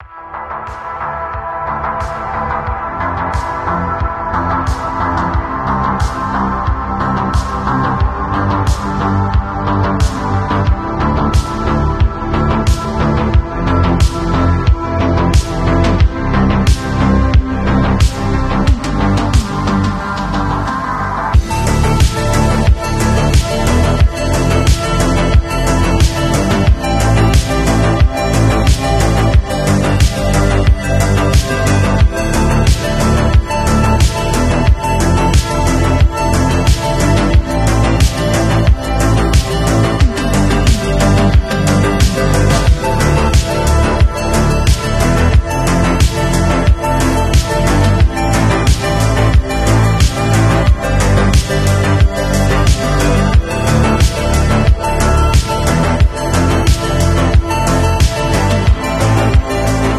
This Synthwave song is a Chill Banger!